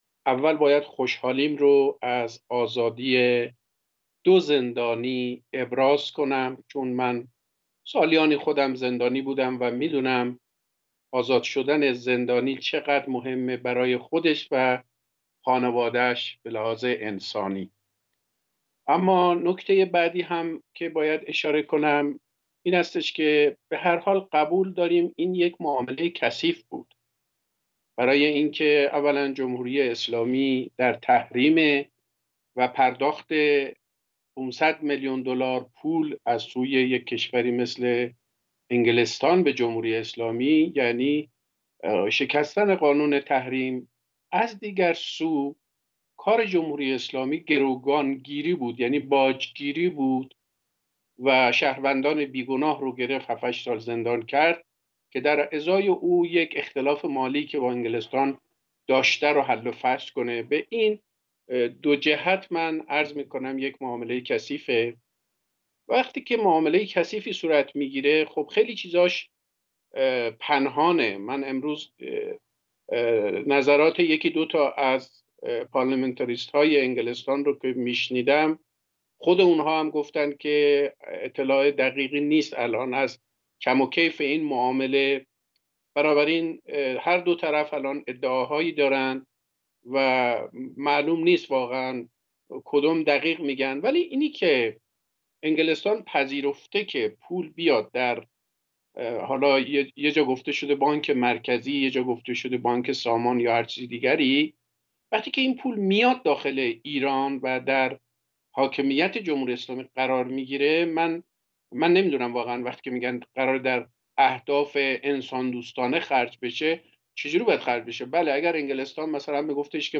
میزگردی